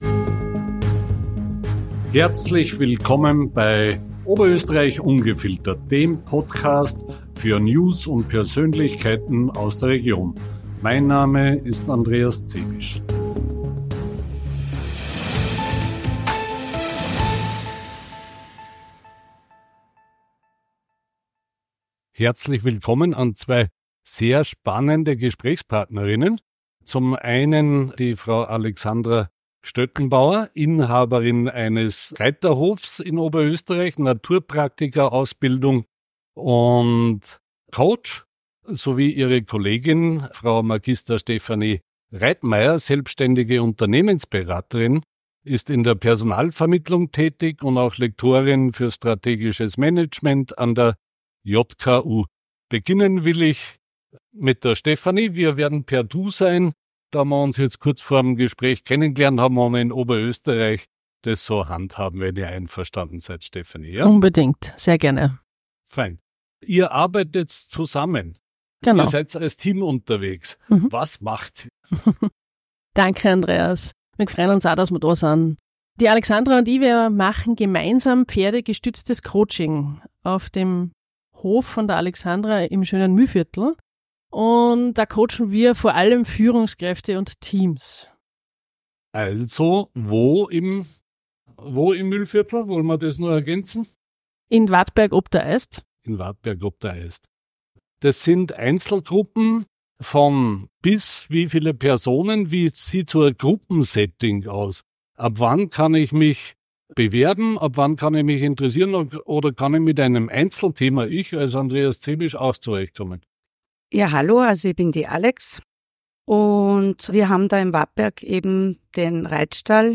Das Gespräch dreht sich um die einzigartige Zusammenarbeit der beiden, ihre langjährige Pferdeerfahrung und warum Pferde als Herdentiere besonders gut für das Coaching von Führungskräften und Teams geeignet sind. Sie erzählen, wie sie mit Hilfe ihrer fünf speziell geeigneten Pferde Vertrauen und Teamgeist in Unternehmen fördern und individuelle Muster und Verhaltensweisen aufdecken.